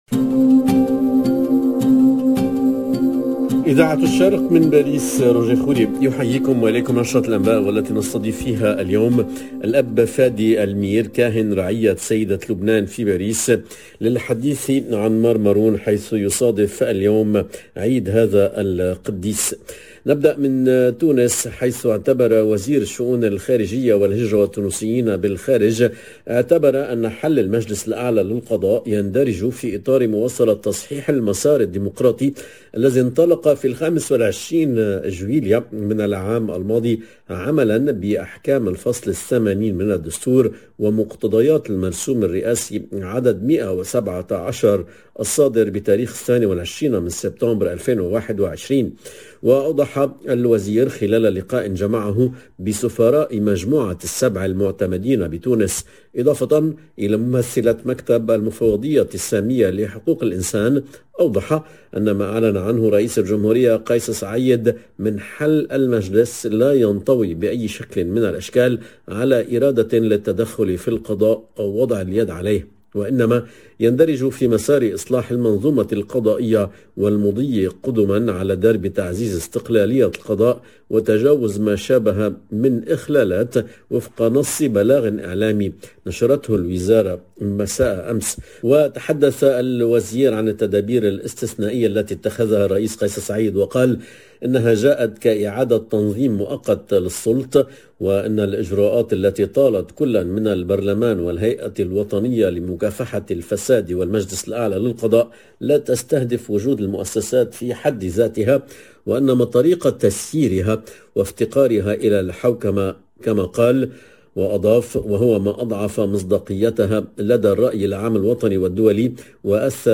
JOURNAL EN LANGUE ARABE وزير الخارجية التونسية يقول خلال لقائه بسفراء مجموعة السبع المعتمدين في تونس إن حل المجلس الأعلى للقضاء يندرج في اطار مواصلة تصحيح المسار